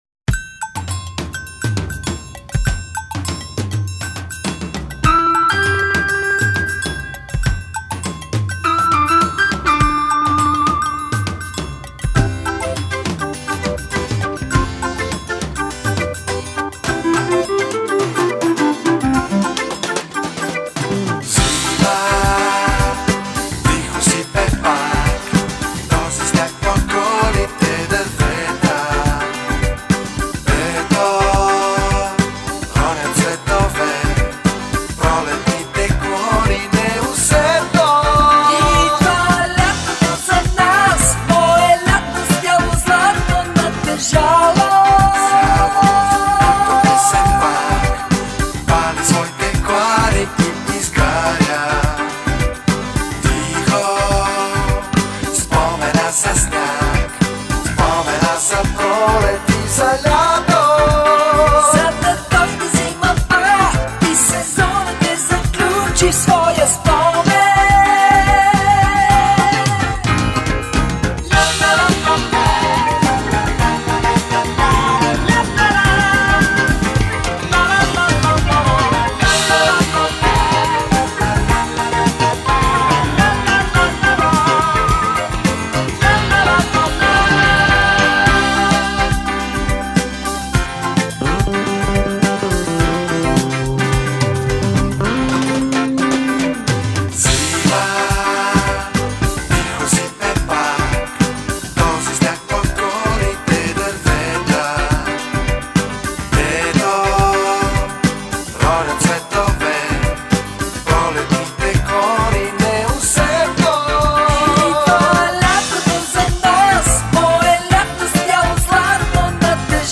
Есть и рок-композиции и джаз-рок. Есть диско-композиции.